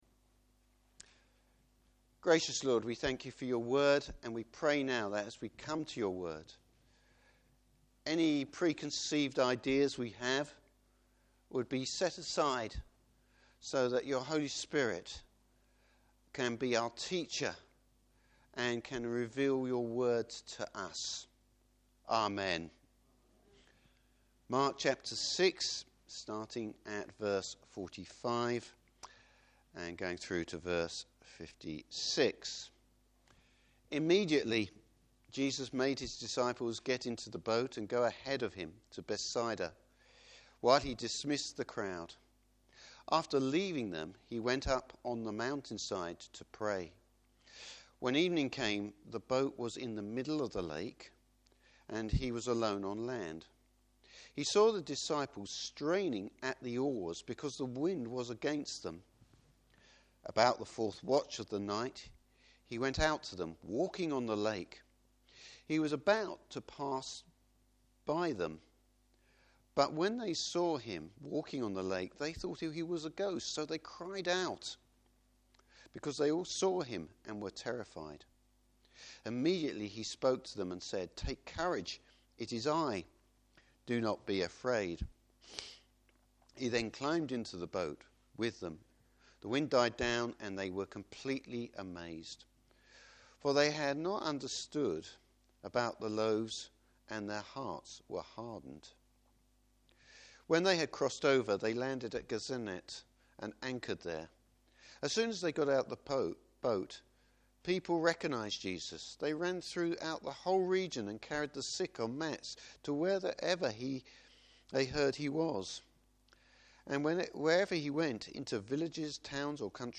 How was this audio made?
Service Type: Morning Service An Old Testament illustration of Christ's divinity.